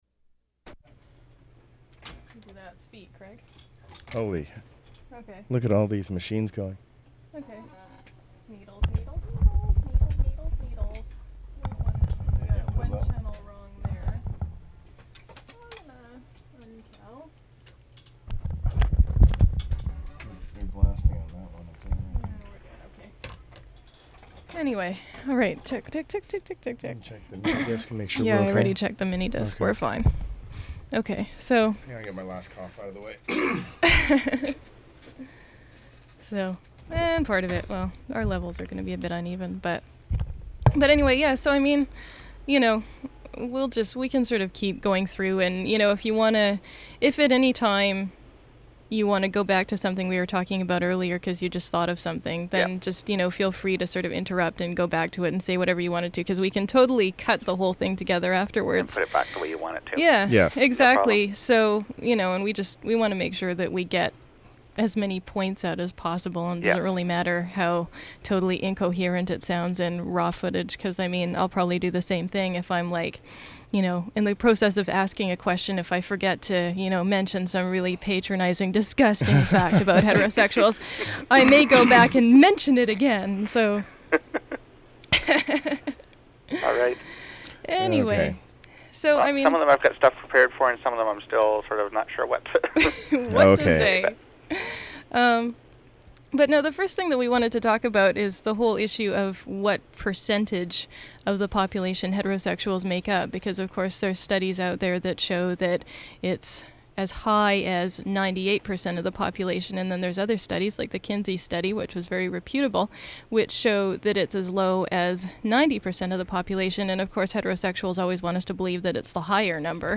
satirical phone interviews
for an early incarnation of CiTR's Queer FM program.